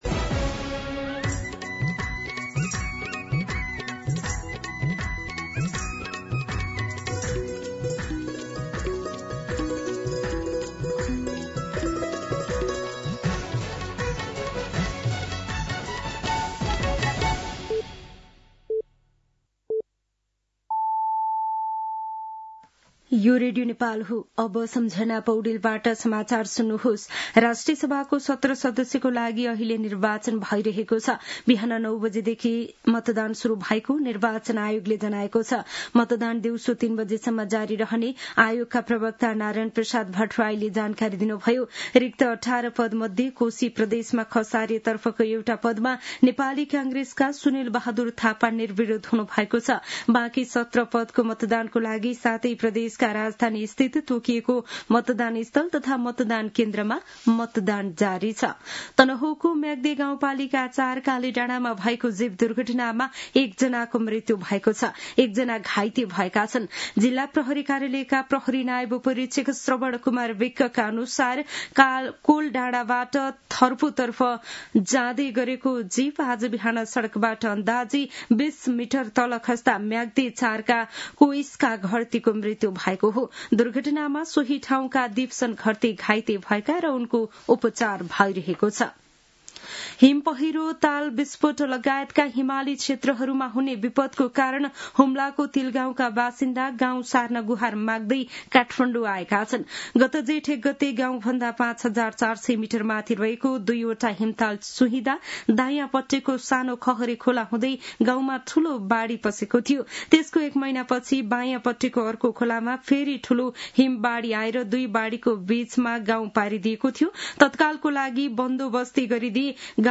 दिउँसो १ बजेको नेपाली समाचार : ११ माघ , २०८२